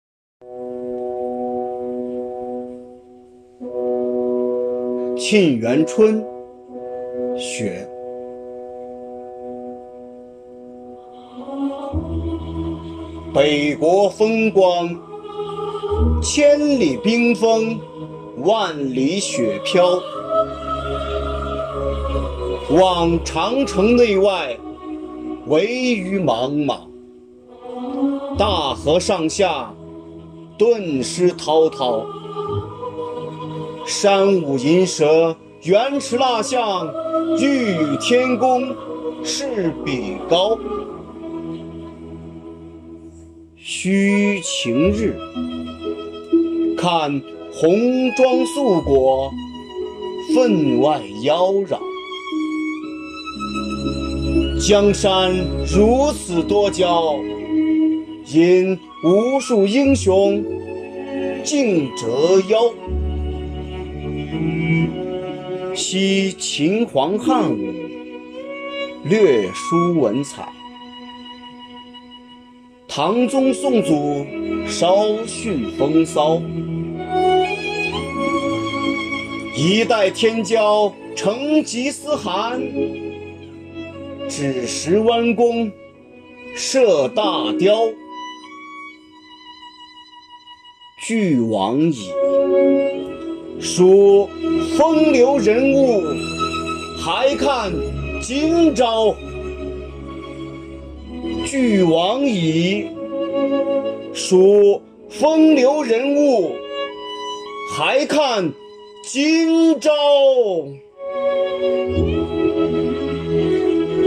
以朗诵为载体，用诗篇来明志
朗诵